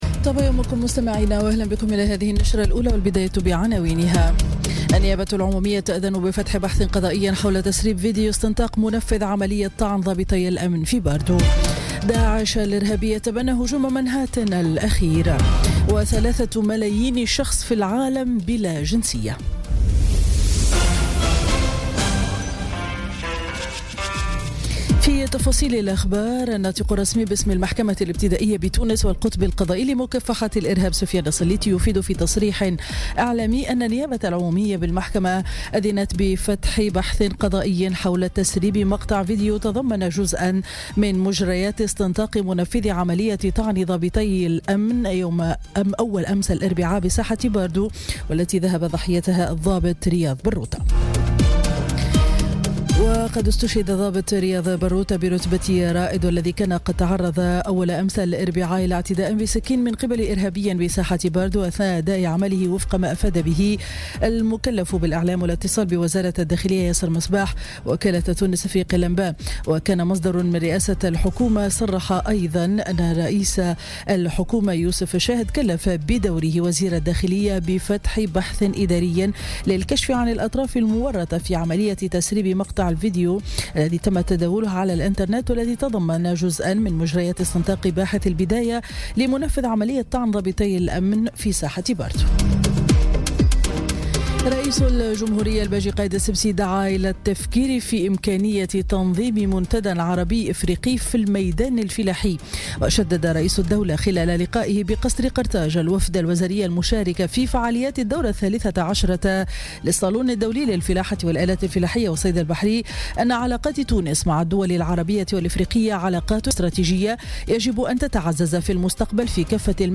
نشرة أخبار السابعة صباحا ليوم الجمعة 3 نوفمبر 2017